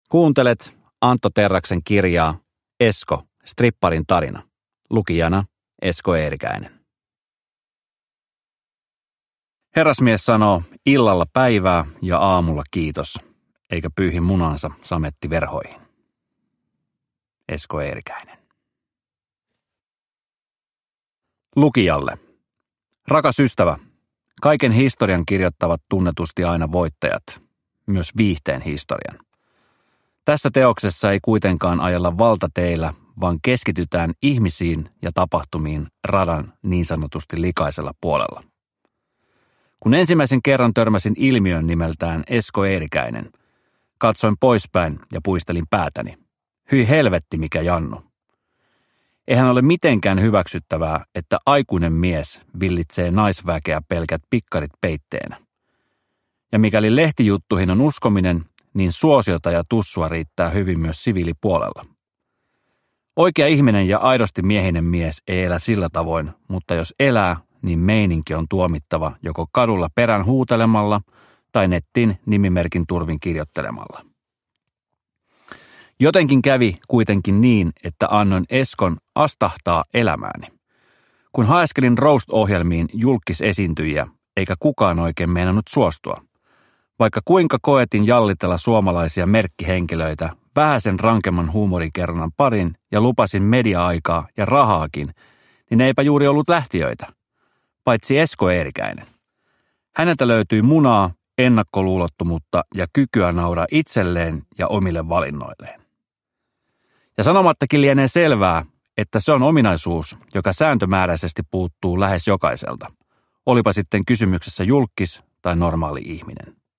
Esko, stripparin tarina – Ljudbok – Laddas ner
Uppläsare: Esko Eerikäinen